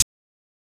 Closed Hats
HI HAT POP.wav